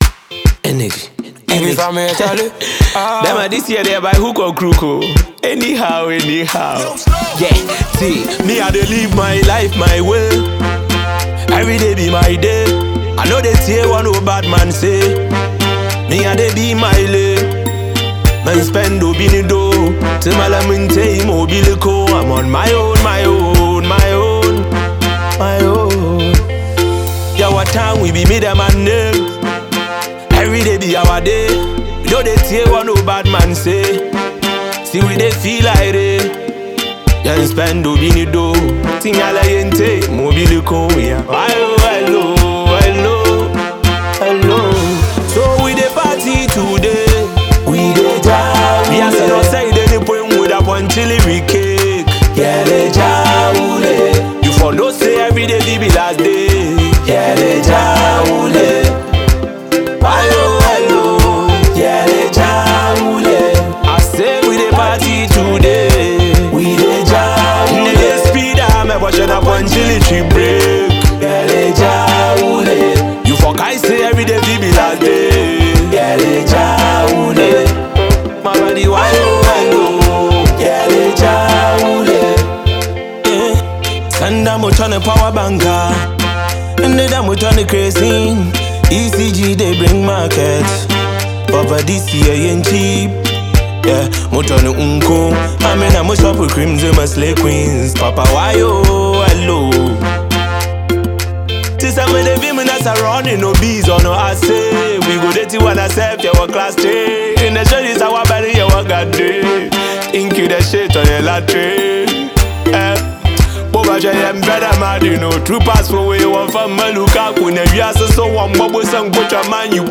Award Winning Fante rapper